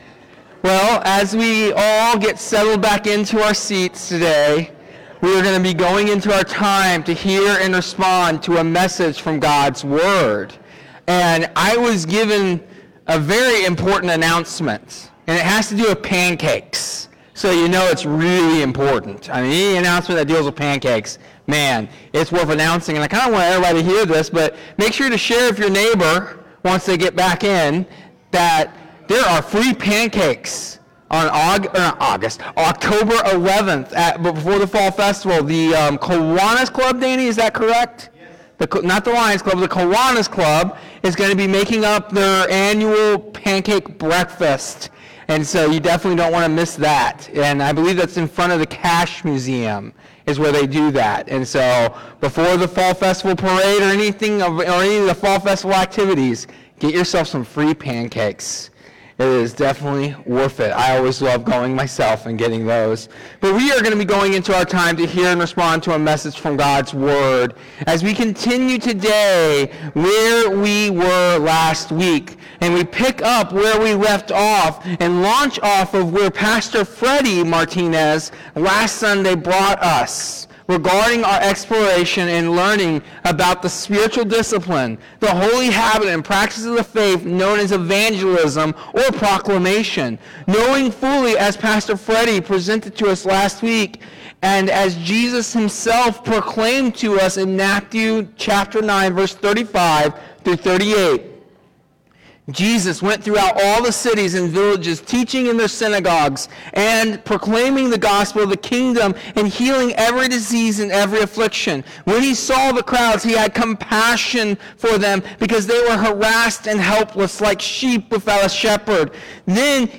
Spiritual Disciplines ~ Evangelism & Proclamation – How - Church of God of Exeter